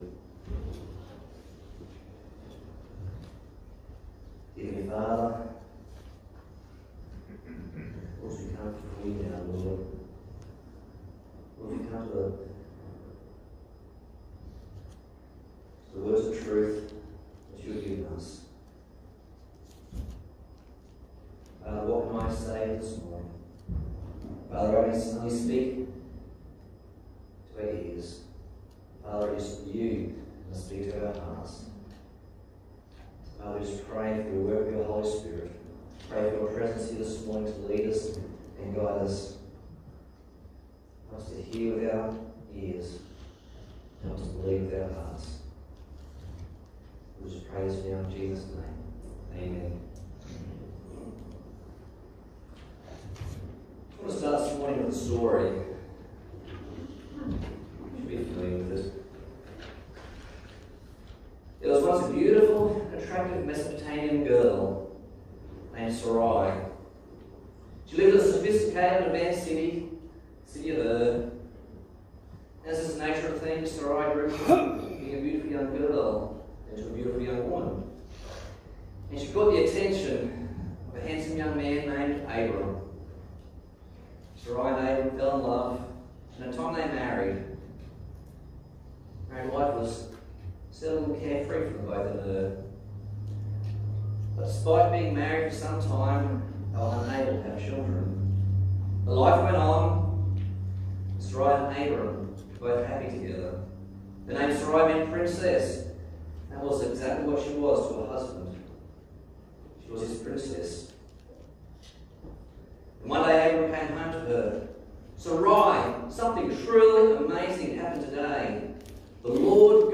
Passage: Genesis 23 Service Type: Sunday Morning